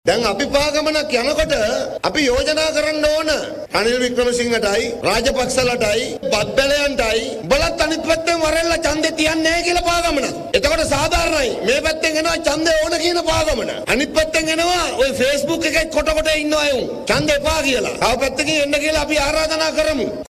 ලාල් කාන්ත මහතා මේ බව සදහන් කළේ මාතලේ පැවති ජන හමුවකට එක්වෙමිනුයි.